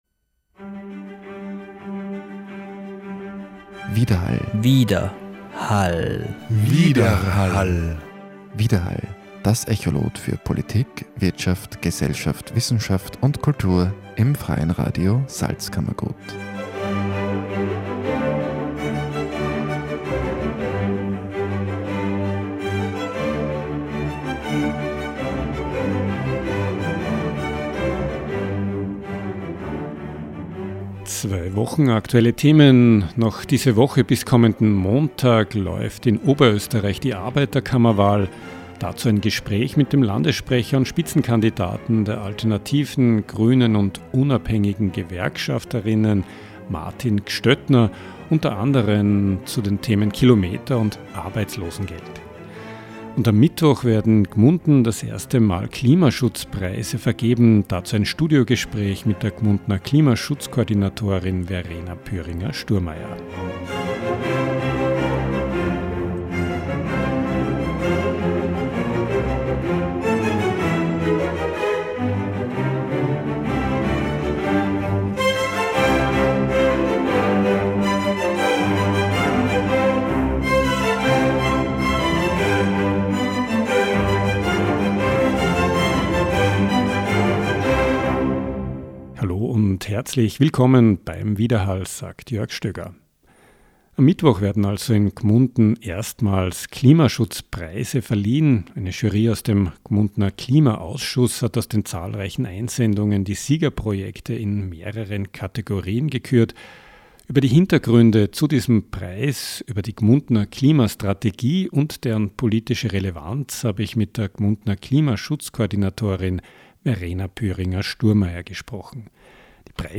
Dazu ein Studiogespräch
Interview